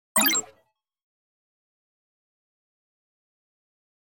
دانلود آهنگ کلیک 3 از افکت صوتی اشیاء
دانلود صدای کلیک 3 از ساعد نیوز با لینک مستقیم و کیفیت بالا
جلوه های صوتی